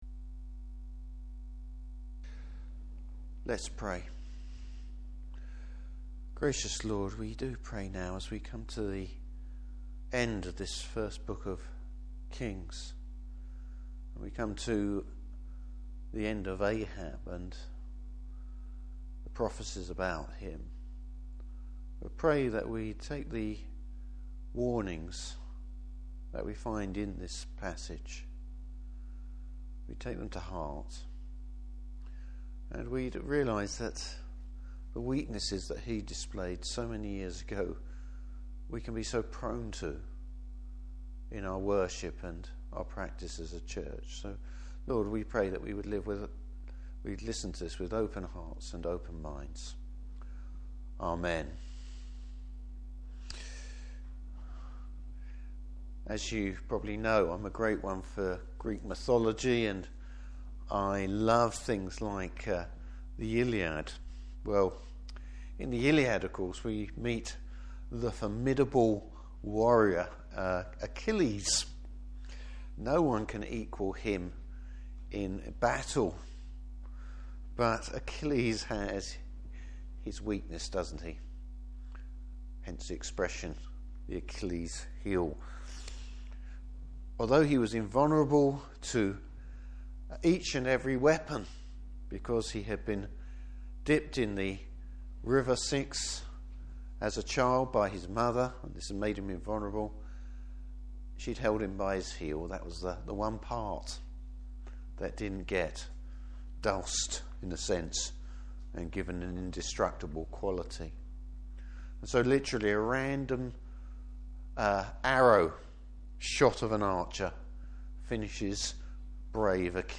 Service Type: Evening Service Bible Text: 1 Kings 22.